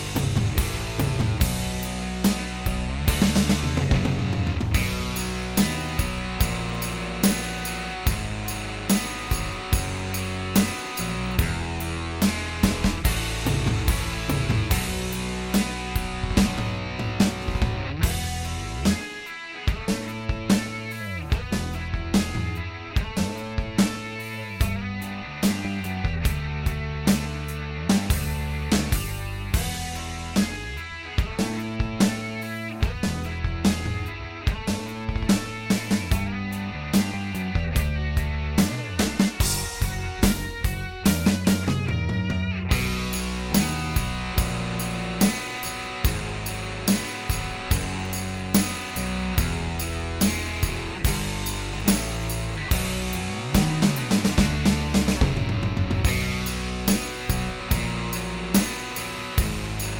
Minus Fuzz Guitar For Guitarists 5:21 Buy £1.50